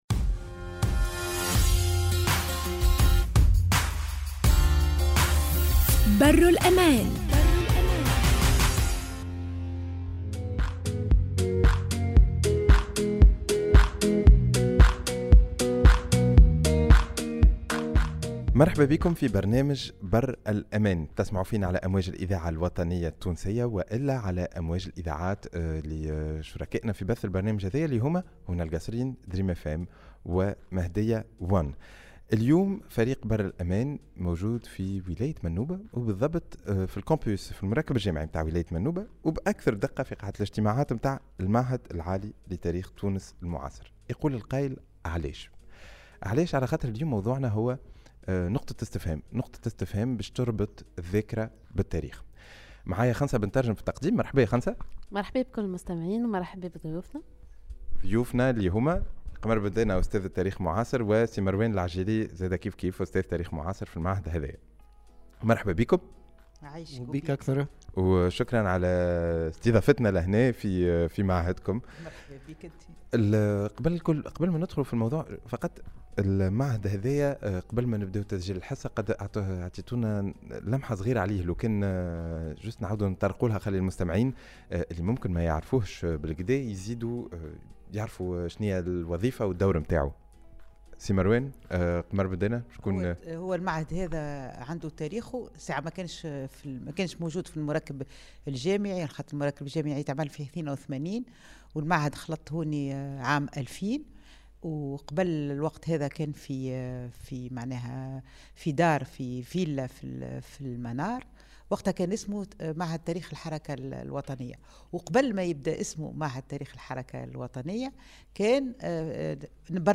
Nous avons réalisé cette émission dans le Campus universitaire de la Manouba dans l’Institut Supérieur d’Histoire de la Tunisie Contemporaine